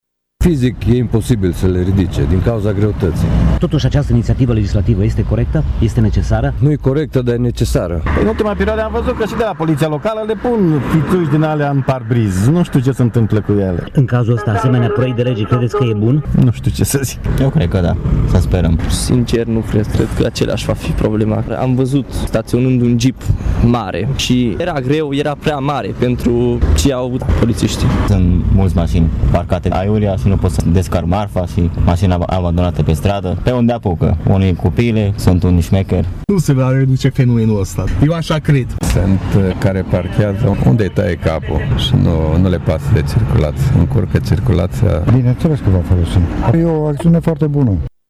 Unii șoferi spun că măsura este inutilă, deoarece ilegalități se vor comite în continuare: